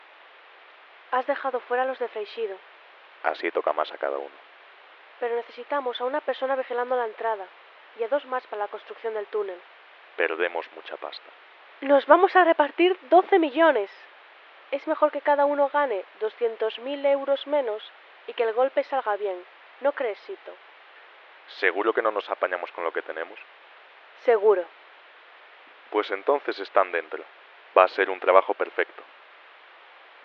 La policía sospecha que se está organizando un atraco y ha pinchado el teléfono de un sospechoso.
Aquí tienes la conversación que han registrado
audio_planeando_atraco_ruido2.mp3